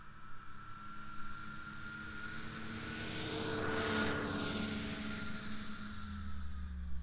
IC Snowmobile Description Form (PDF)
IC Subjective Noise Event Audio File - Run 4 (WAV)